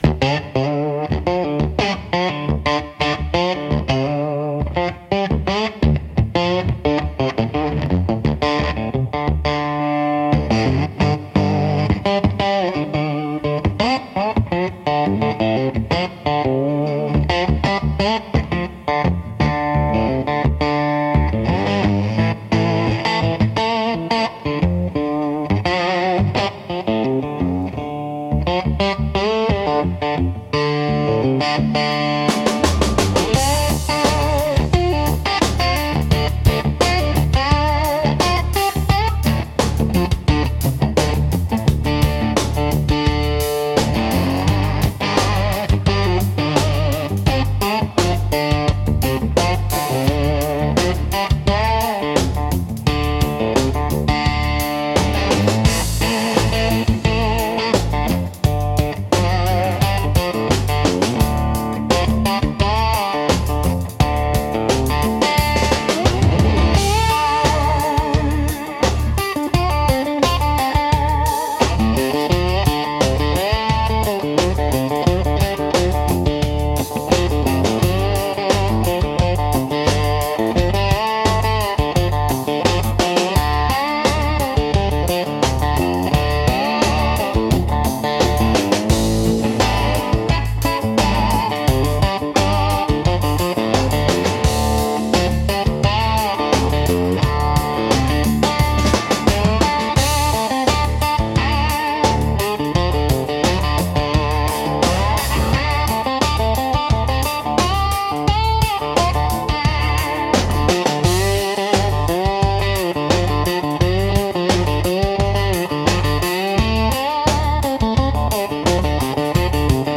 Instrumental - Swamp Dust Rising 4.33